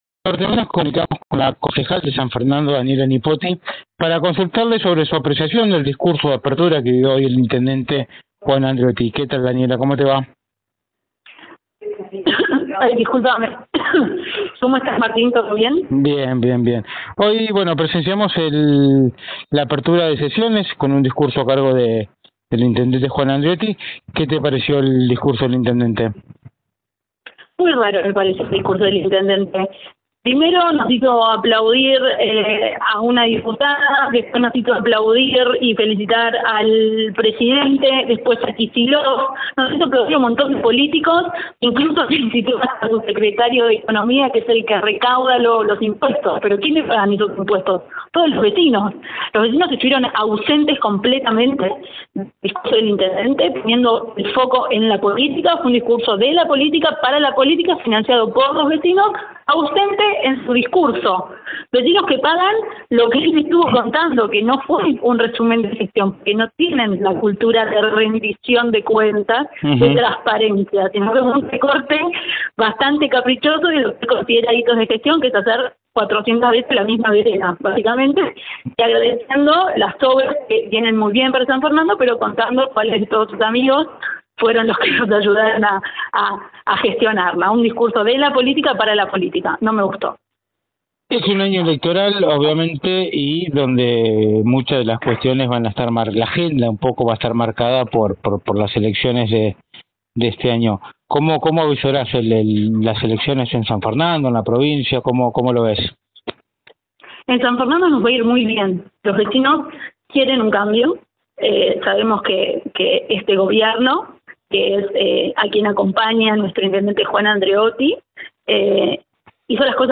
La concejal de Juntos por el Cambio en San Fernando mantuvo un diálogo con NorteOnline, donde realizó duras críticas a la apertura de sesiones ordinarias a cargo del intendente.